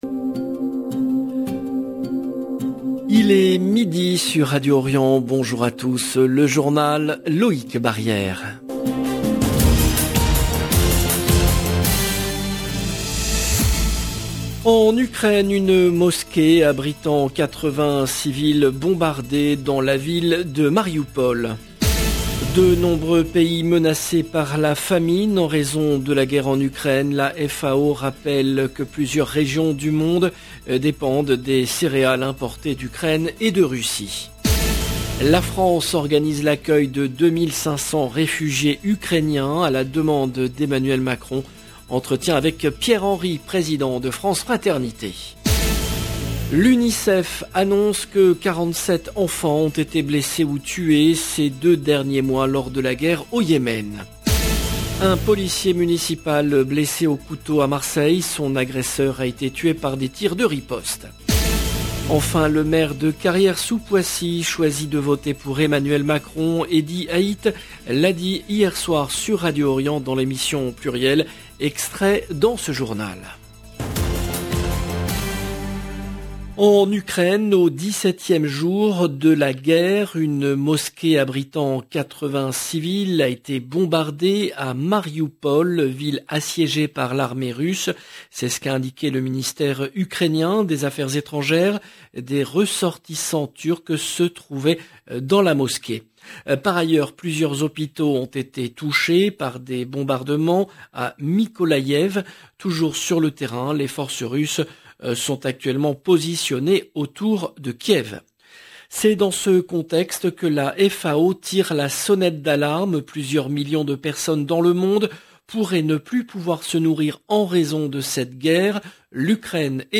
LE JOURNAL EN LANGUE ARABE DE MIDI 30 DU 12/03/22